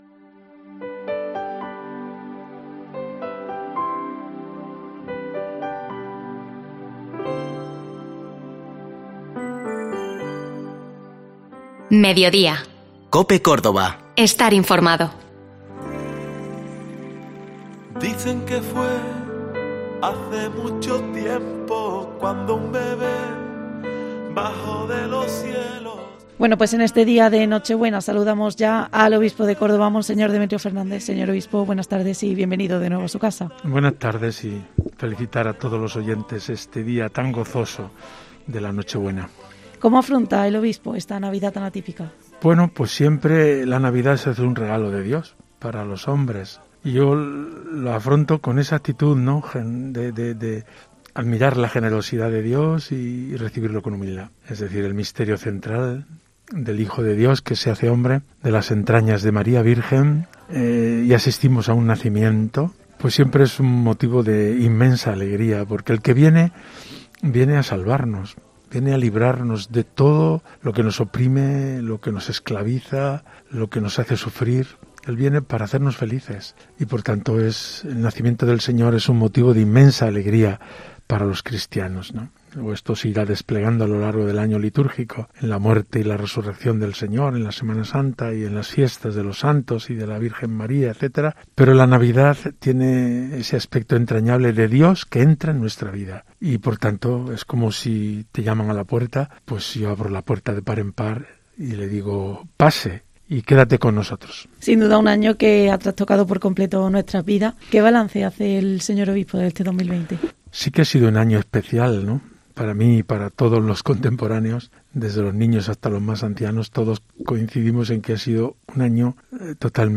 El prelado cordobés ha visitado los estudios de COPE, donde ha analizado este año 2020 marcado por la pandemia de la COVID.